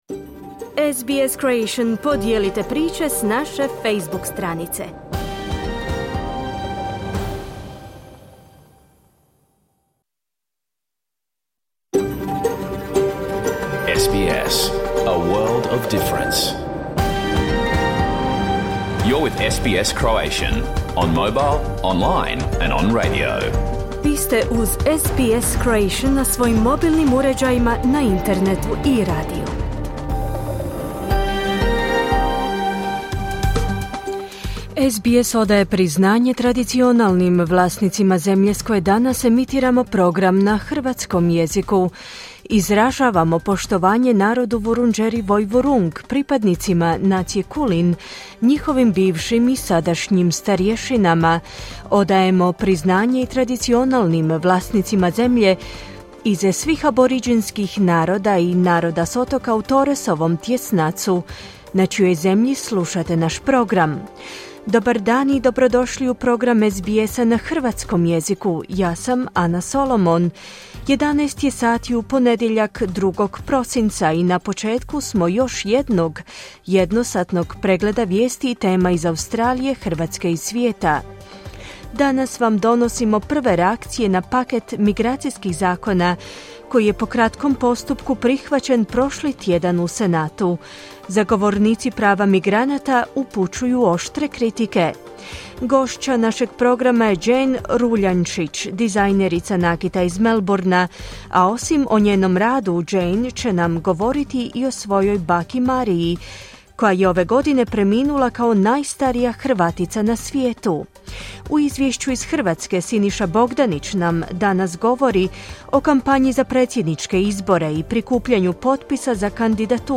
Pregled vijesti i aktualnosti iz Australije, Hrvatske i ostatka svijeta. Emitirano na radiju SBS1 u 11 sati, po istočnoaustralskom vremenu.